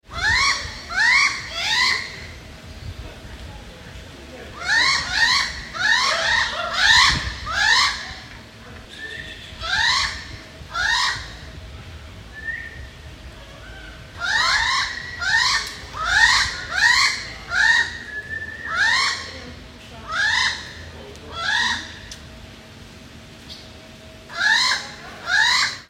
Tropical Bird Calls Sound Effect
Description: Tropical bird calls sound effect. An original and authentic recording of birds in the natural environment of the Dominican Republic. Bird sounds.
Tropical-bird-calls-sound-effect.mp3